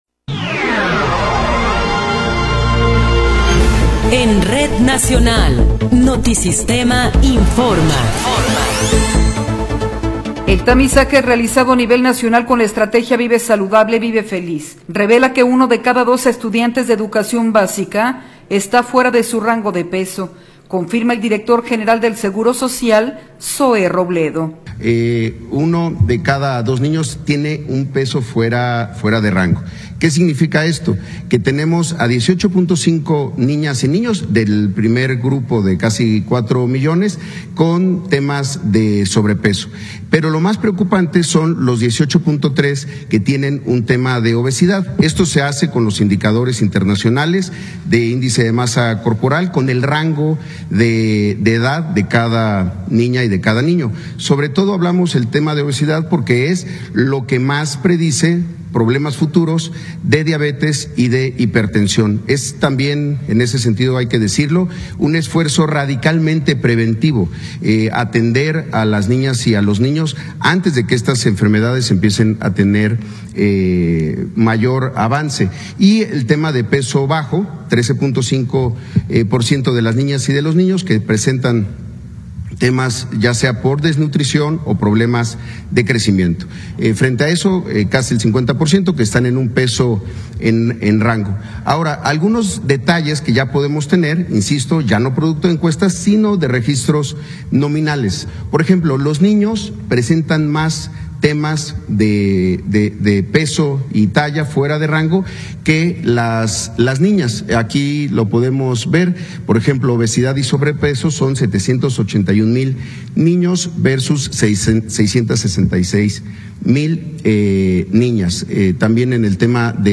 Noticiero 15 hrs. – 1 de Enero de 2026